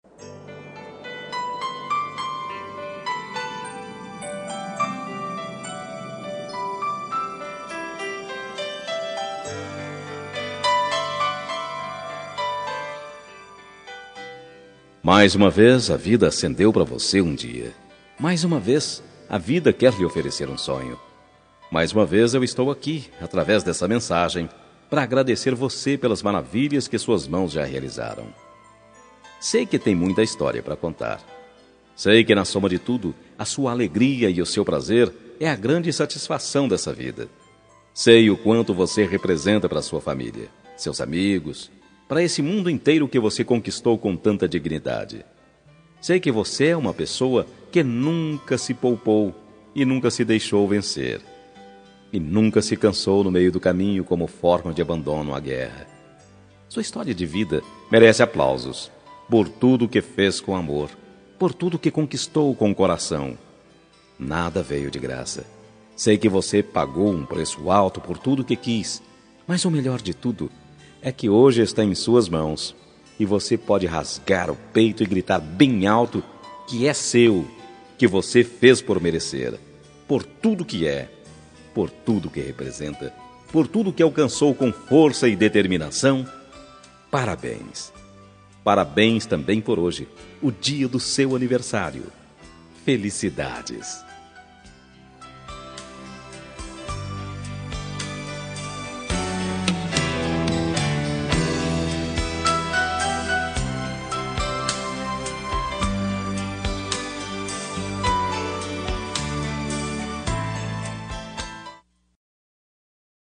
Aniversário de Avô – Voz Masculina – Cód: 2108 – Linda